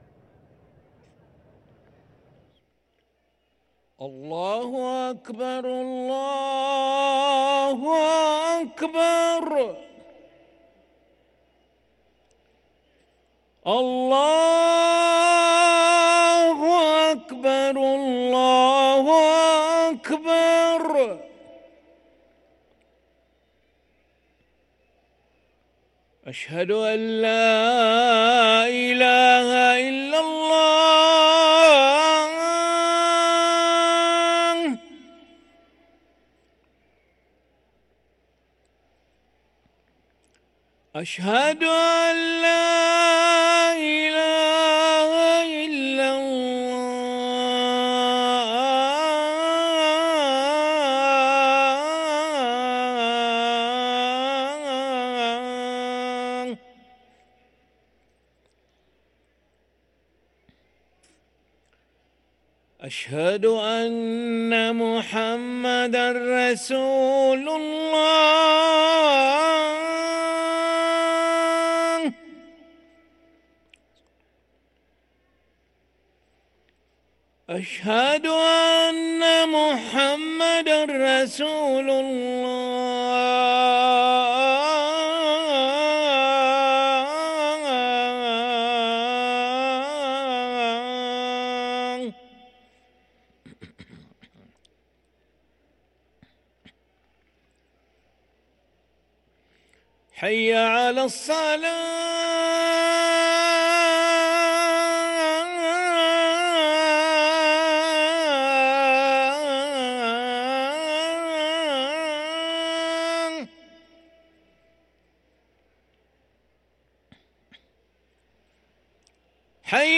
أذان العشاء للمؤذن علي أحمد ملا الخميس 28 شوال 1444هـ > ١٤٤٤ 🕋 > ركن الأذان 🕋 > المزيد - تلاوات الحرمين